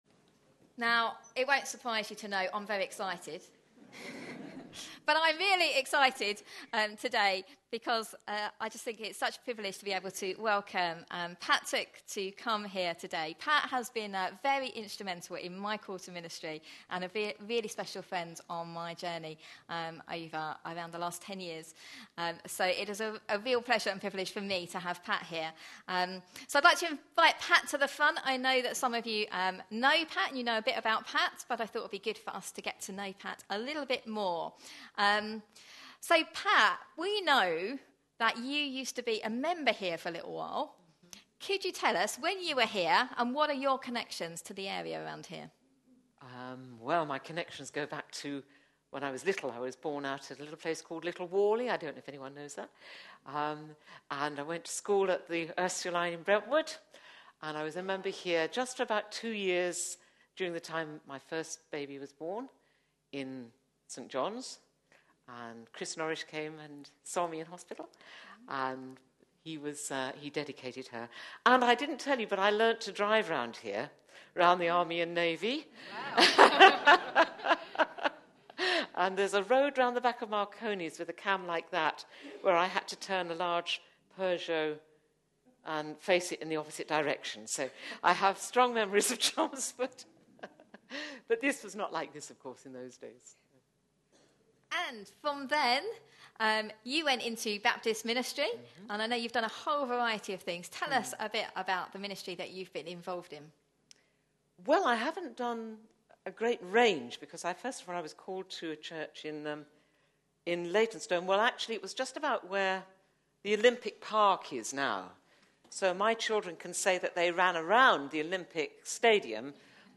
A sermon preached on 11th May, 2014, as part of our 109th Church Anniversary series.
Luke 16:1-8 Listen online Details Reading is Luke 16:1-8 (variously translated as "The Shrewd Manager" or "Dishonest Steward"), with references to the three parables in Luke 15 (Lost Sheep, Lost Coin, & Lost or 'Prodigal' Son), and Jesus's words in Luke 16:15. The recording starts with a short (5 min) interview.